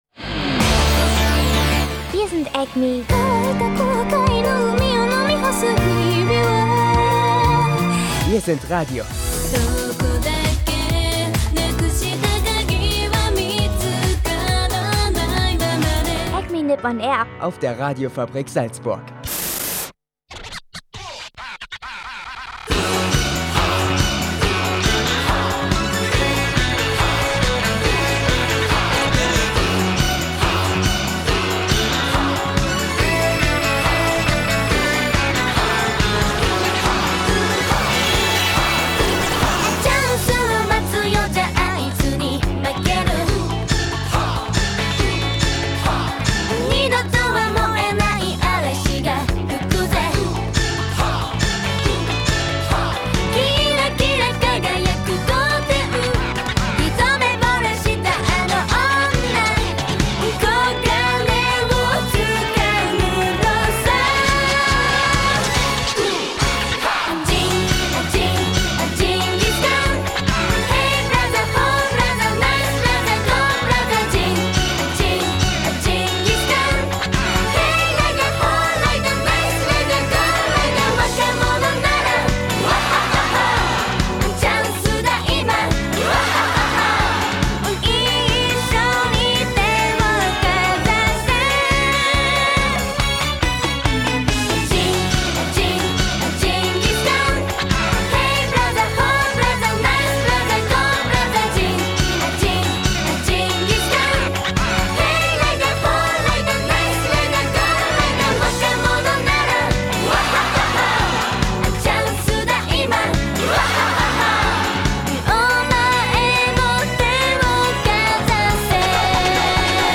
Nach der großen Jubiläums-Doppelsendung gibt es diese Woche wieder recht normales Programm. Dabei aber mit Musik, dessen Animes man unbedingt mal schauen sollte, AnimeNews unter anderem zu Lady Gaga und dem Pen-Pineapple-Apple-Pen-Sänger und Japan-News und Wetter